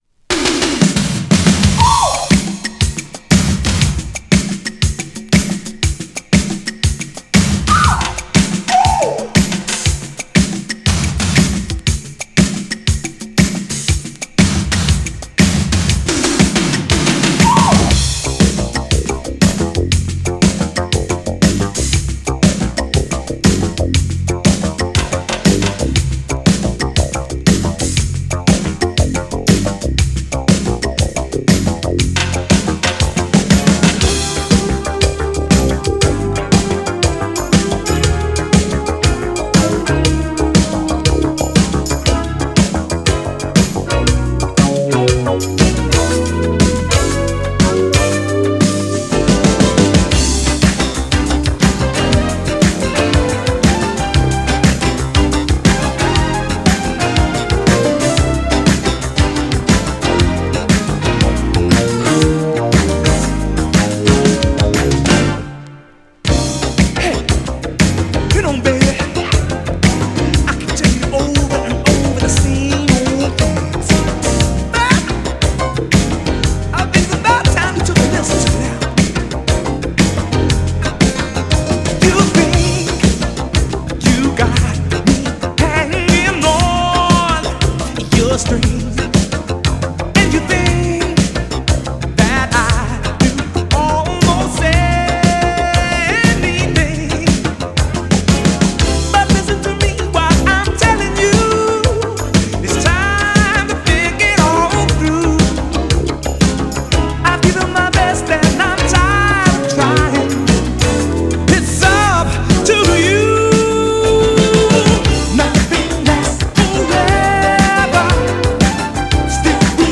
モダンなダンスチューン!!
GENRE Dance Classic
BPM 116〜120BPM